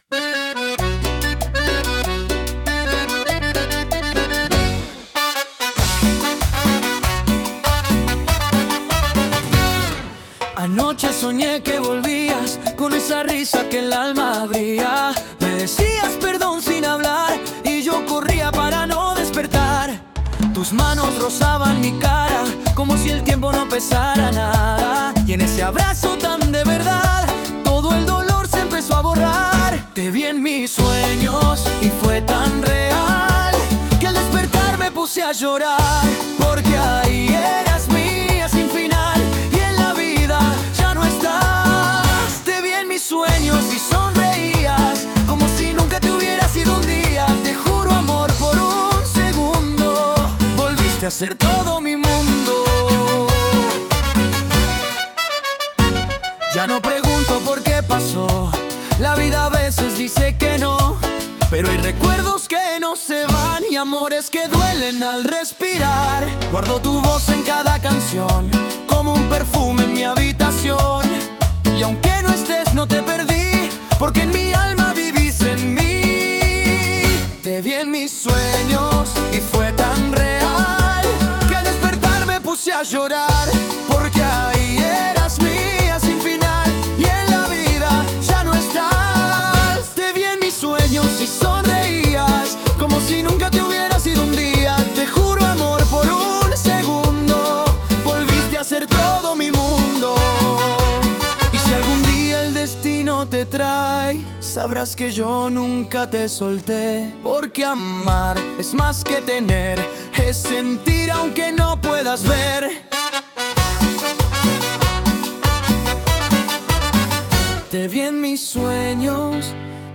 aquí les paso por si quieren tenerla a la mano el archivo de la primer canción que cree usando el modelo v4.5All
Te ví en mi Sueño (Cumbia Uruguaya con Voces Masculinas) (Cover).mp3